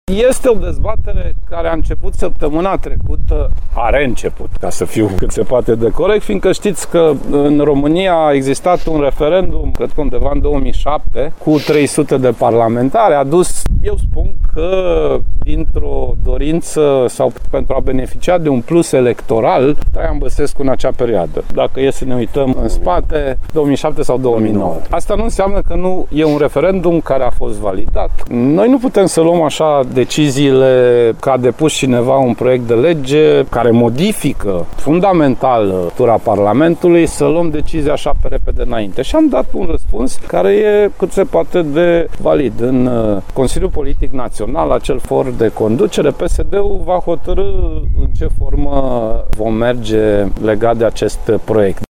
Președintele interimar al PSD a declarat, la Timișoara, că o decizie în acest sens va fi luată în Consiliul Național Politic.
Anunțul a fost făcut, la Timișoara, de preşedintele interimar al PSD, Sorin Grindeanu.